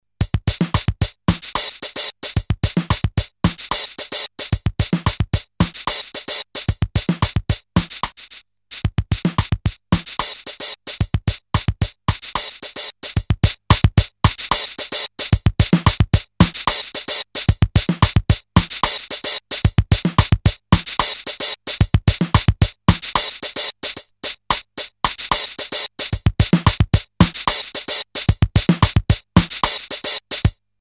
Programmable Digital Drum Machine (1981)
Classic American drum machine based on primitive 8-bits samples but great sound: this is the history of eighties music from New Order to Run DMC.
demo OB DMX + Bass station II
pattern demo1